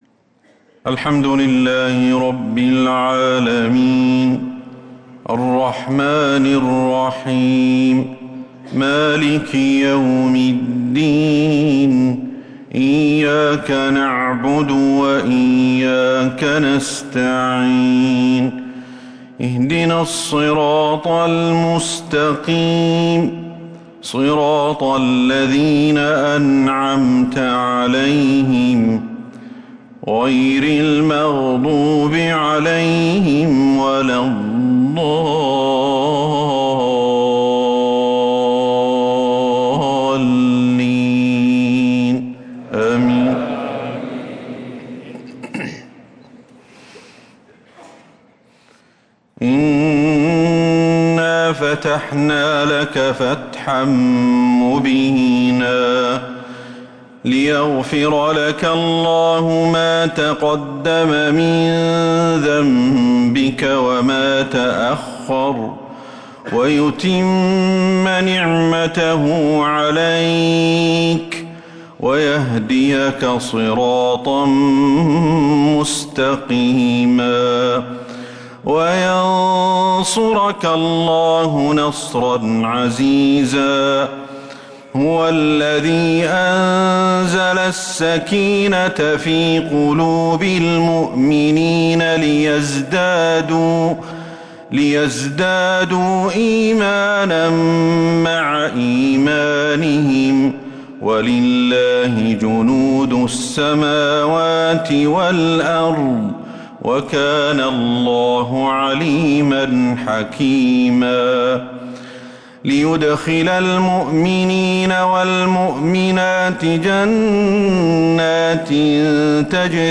عشاء الجمعة 8-8-1446هـ فواتح سورة الفتح 1-10 | Isha prayer from Surah al-Fath 7-2-2025 > 1446 🕌 > الفروض - تلاوات الحرمين